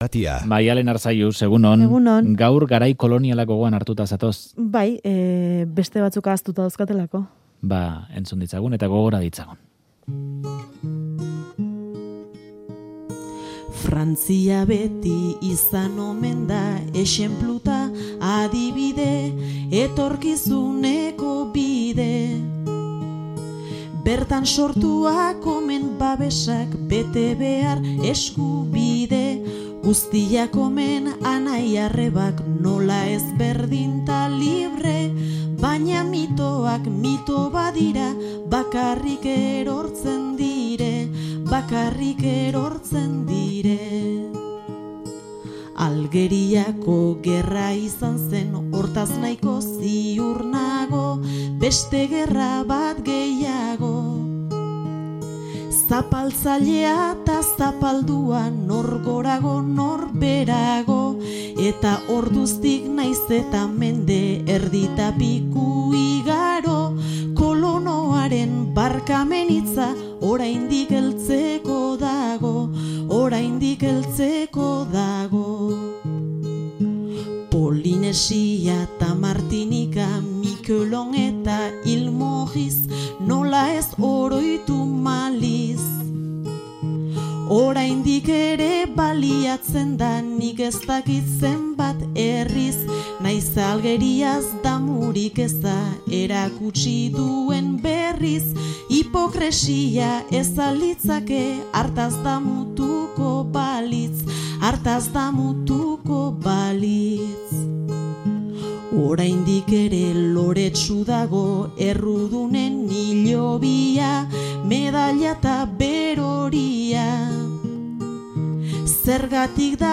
kantua